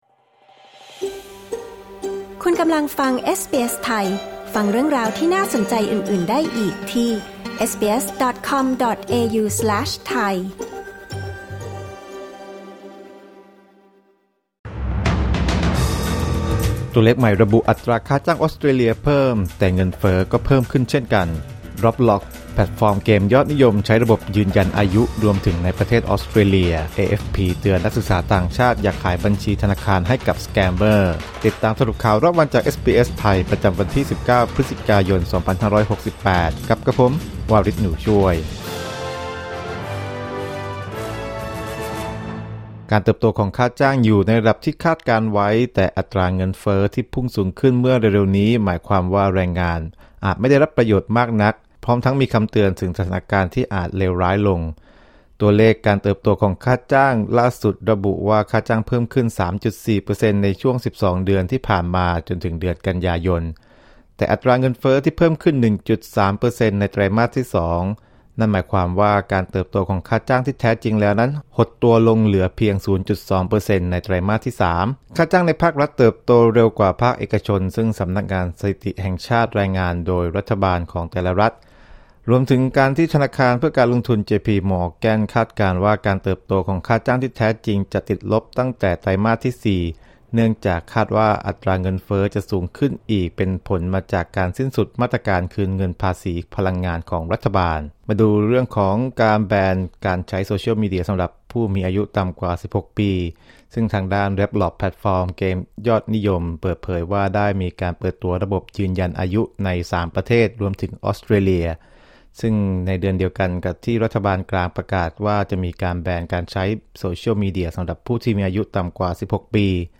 สรุปข่าวรอบวัน 19 พฤศจิกายน 2568